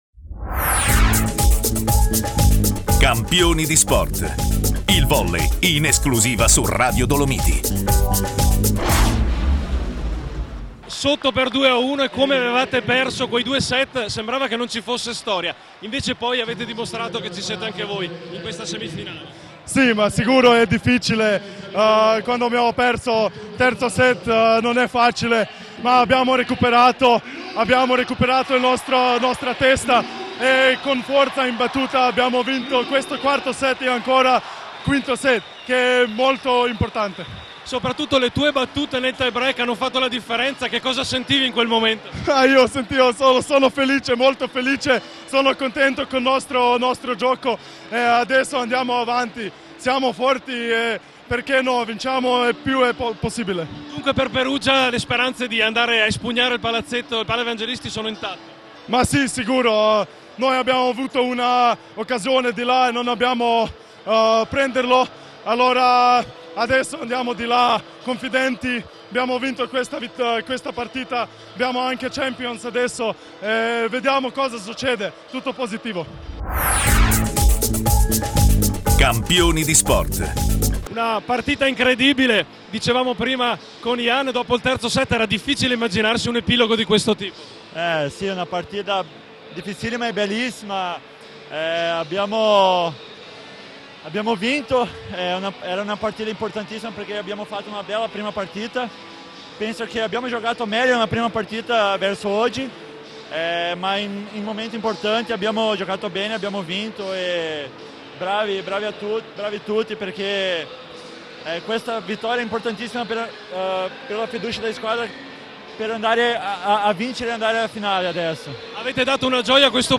Mp3 interview